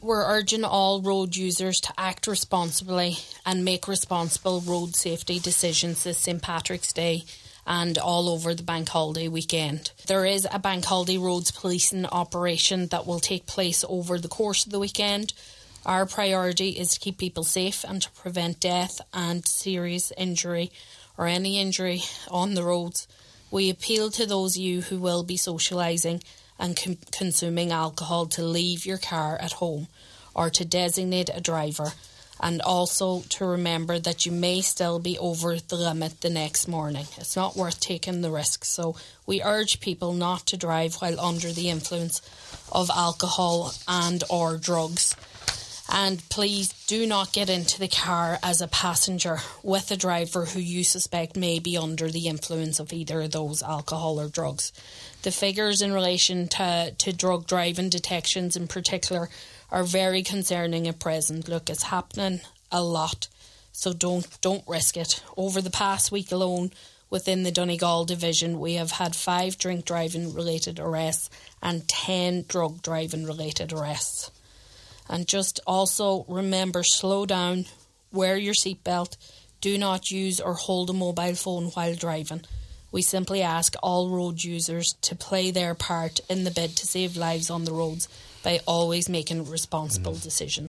The figures were revealed on today’s Nine til Noon Show